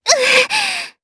Talisha-Vox_Damage_Jp_01.wav